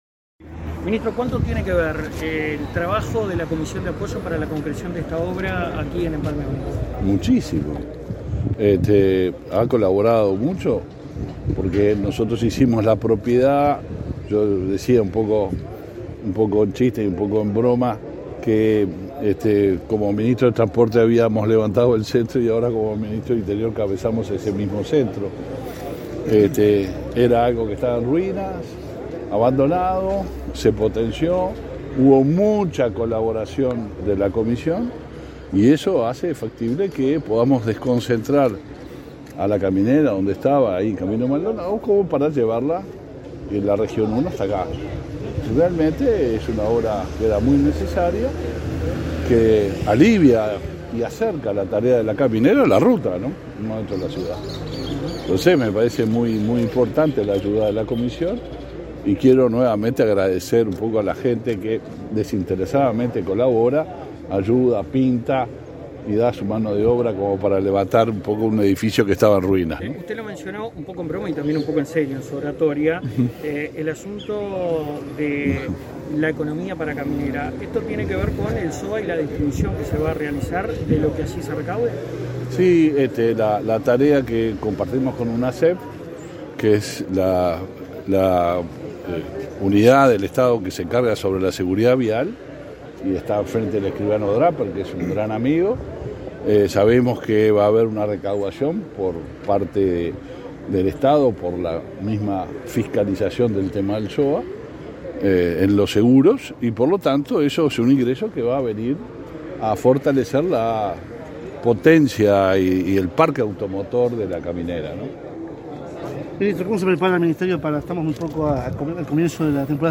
Declaraciones a la prensa del ministro del Interior, Luis Alberto Heber